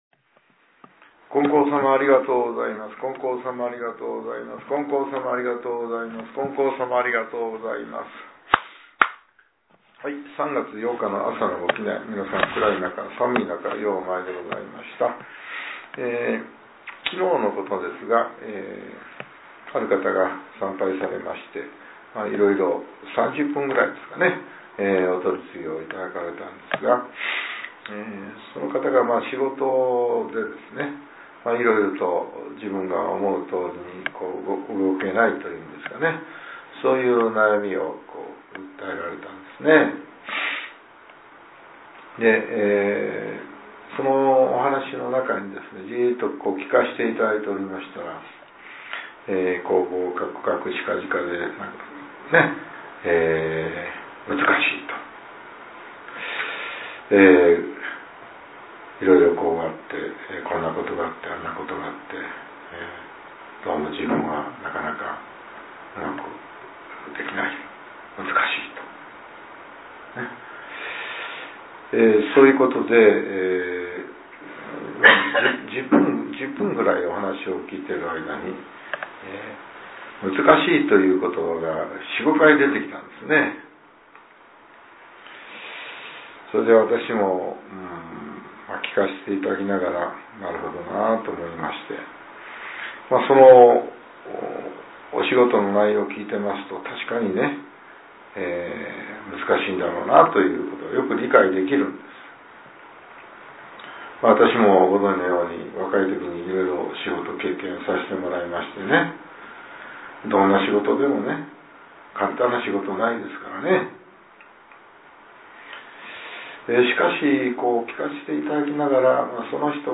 令和８年３月８日（朝）のお話が、音声ブログとして更新させれています。